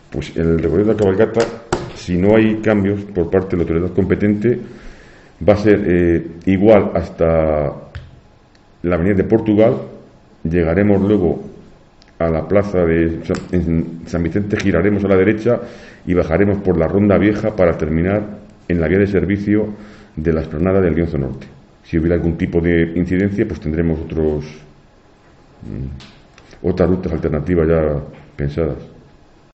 Félix Meneses, concejal de Fiestas. Cabalgata Reyes